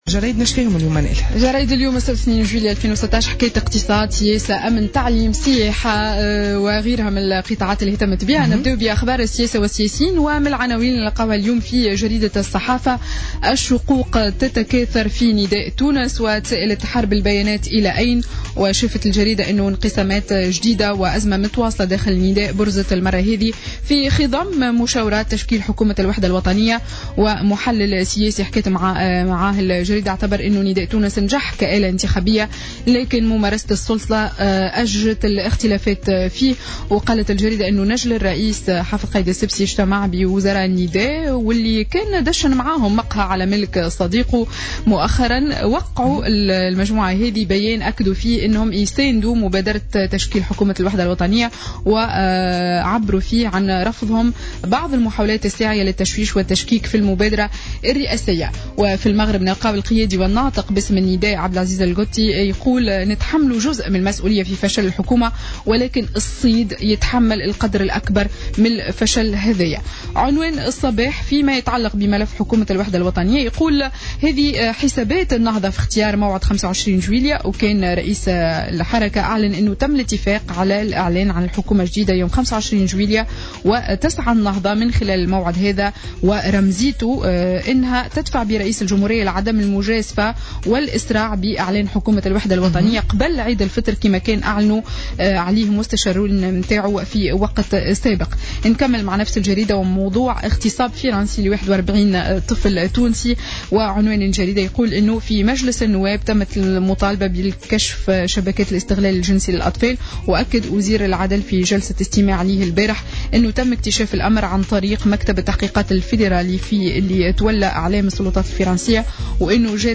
Revue de presse du samedi 2 juillet 2016